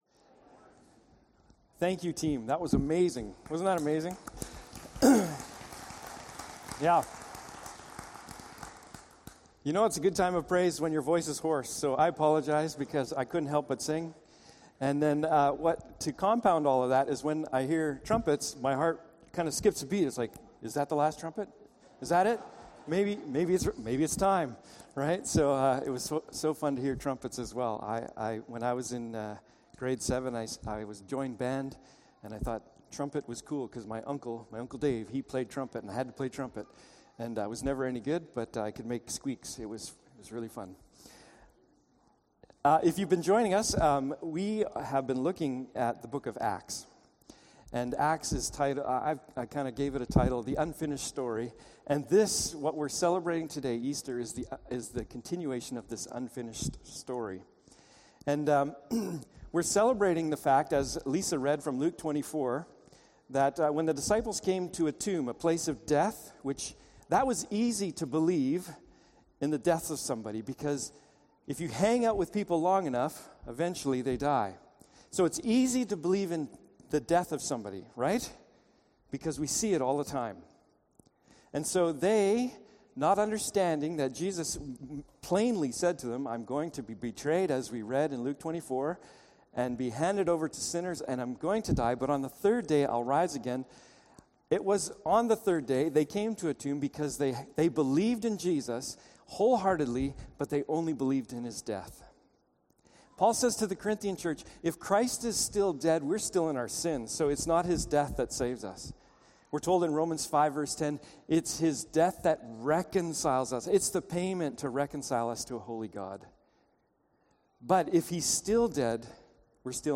Acts 9:1-2 Service Type: Morning Service « Acts of Jesus